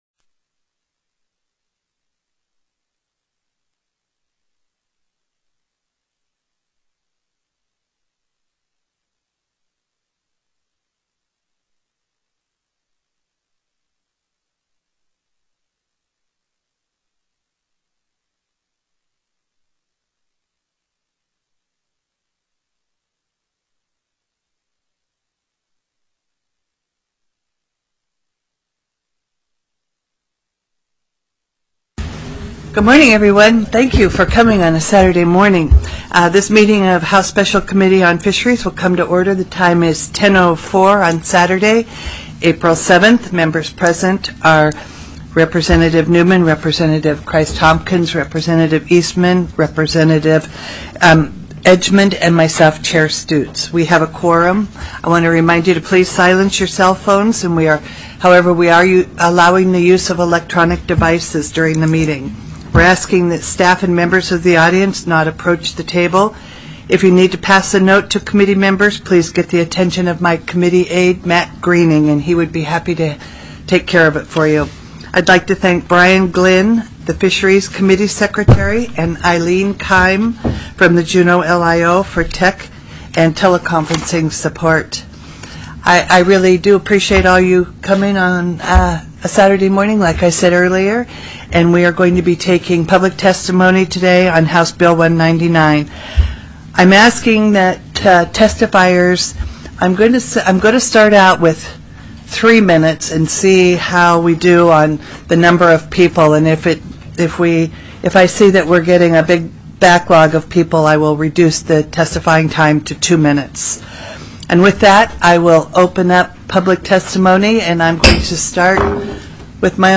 04/07/2018 10:00 AM House FISHERIES
The audio recordings are captured by our records offices as the official record of the meeting and will have more accurate timestamps.
Public Testimony